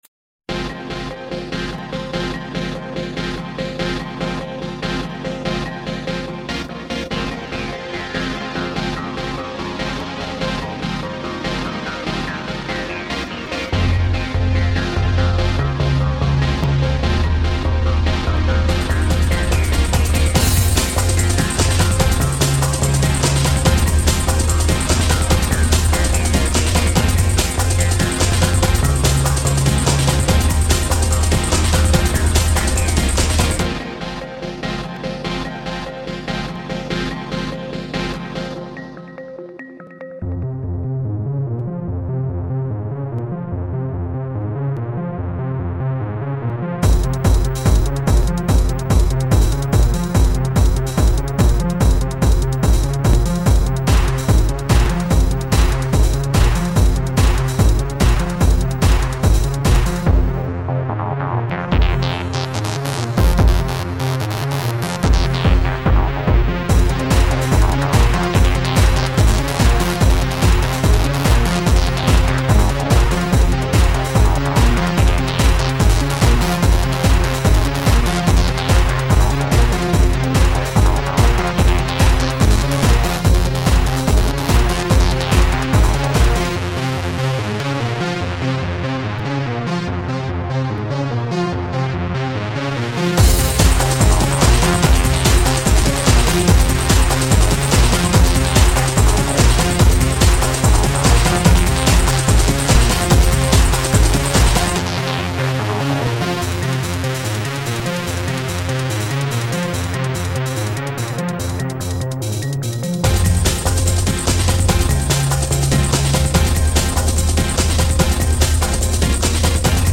First hard style mix!